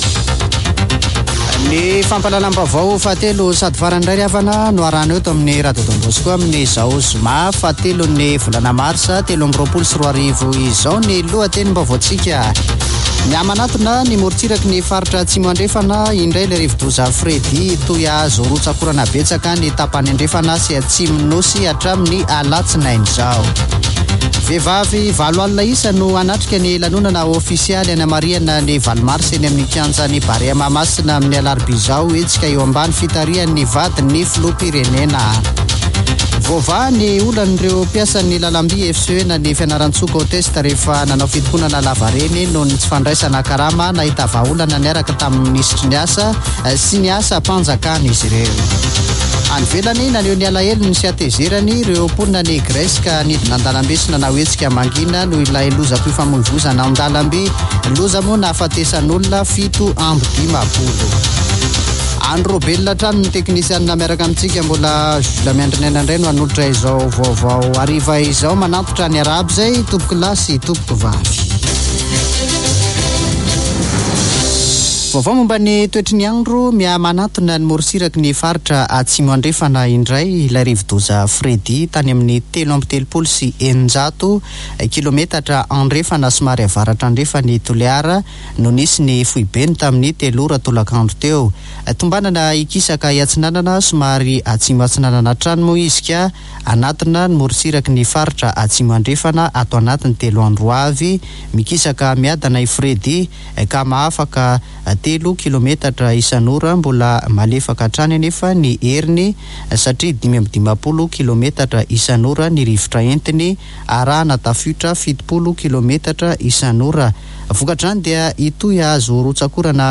[Vaovao hariva] Zoma 3 marsa 2023